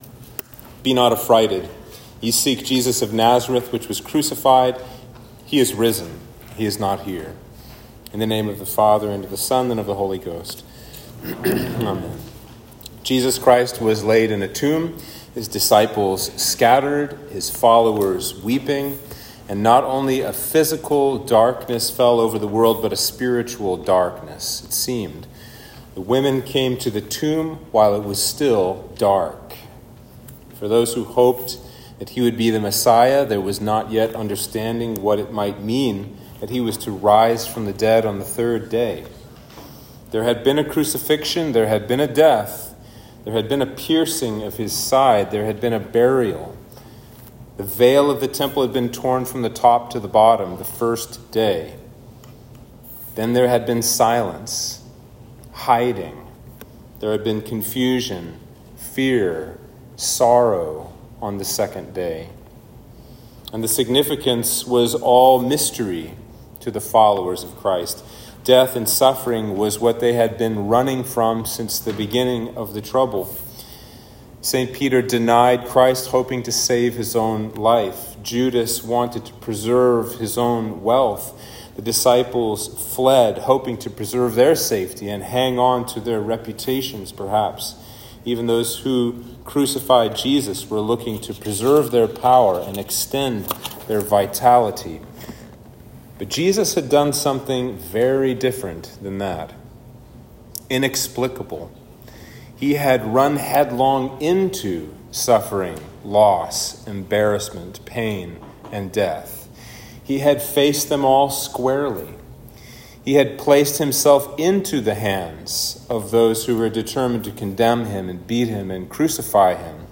Sermon for Easter Vigil